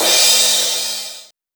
• Short Reverb Crash Cymbal Sound C Key 04.wav
Royality free drum crash sound tuned to the C note. Loudest frequency: 6293Hz
short-reverb-crash-cymbal-sound-c-key-04-SHO.wav